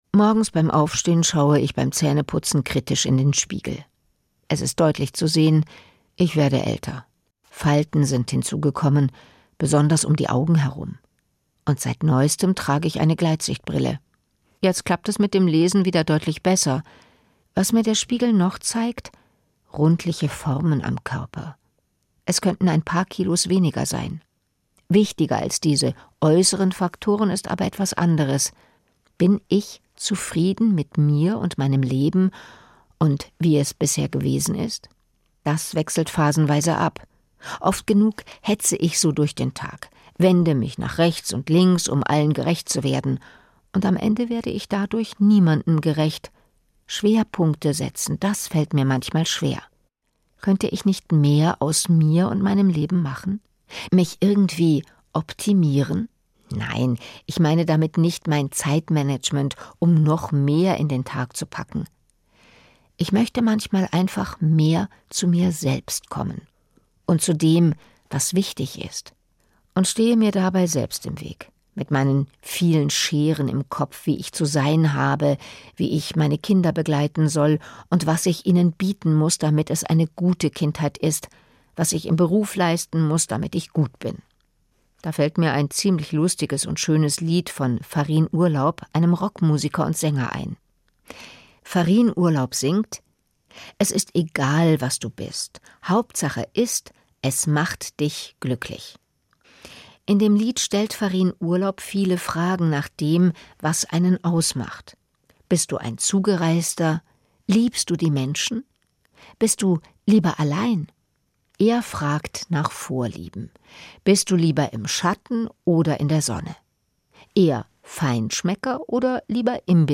Sprecherin